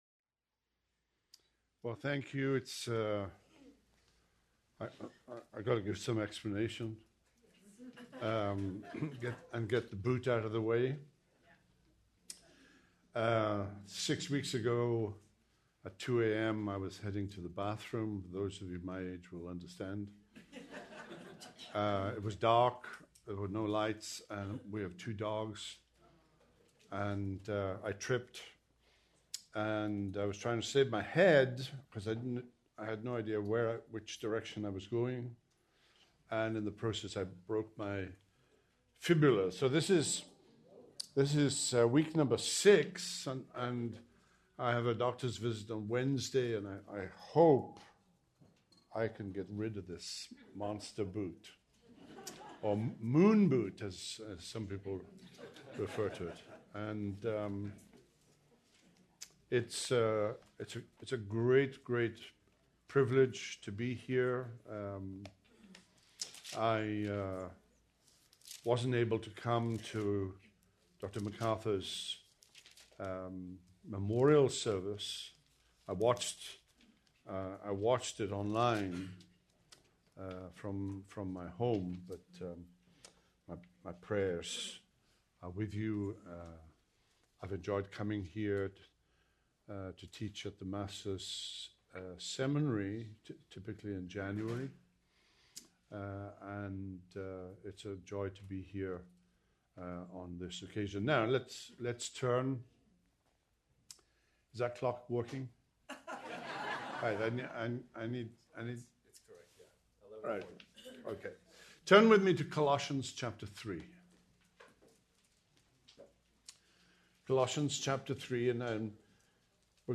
Due to technical difficulties, this sermon is incomplete.